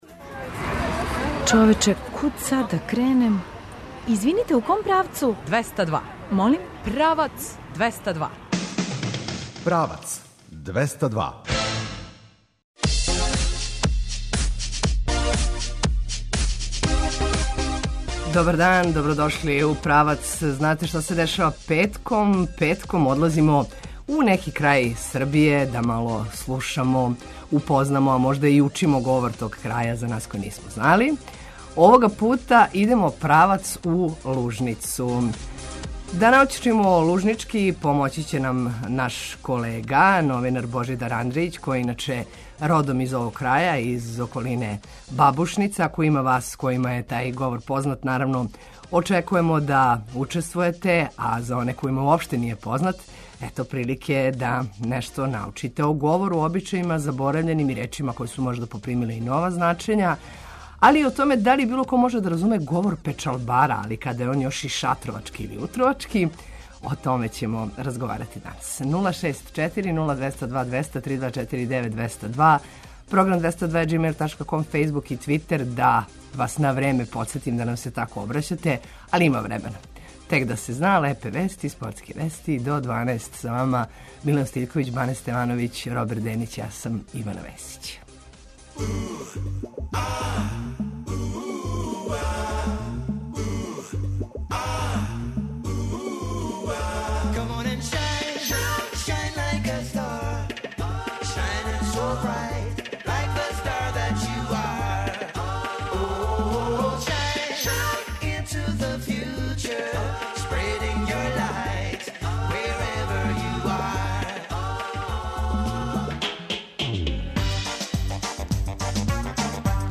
Овога пута идемо правац у Лужницу да слушамо, упознамо и учимо лужнички говор.